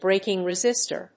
BRAKING+RESISTOR.mp3